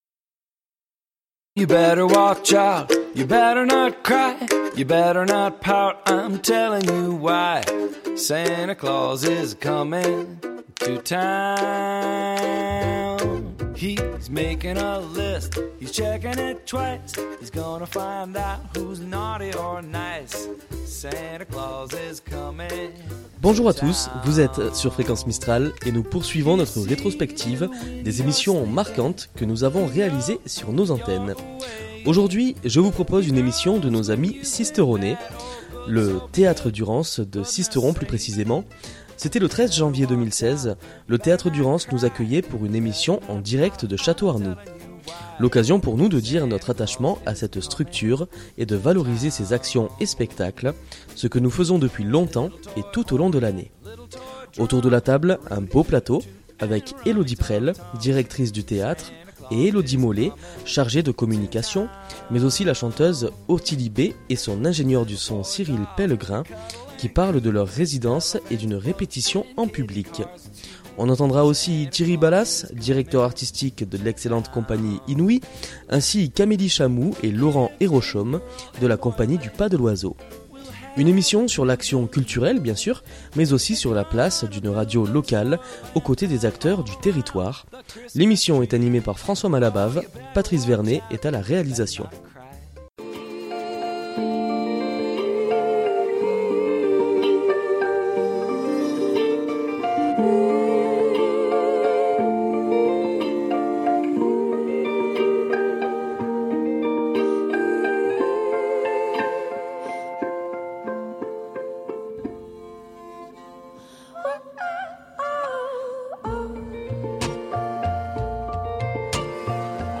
Le 13 janvier 2016, le Théâtre Durance nous accueillait pour une émission en direct de Château-Arnoux.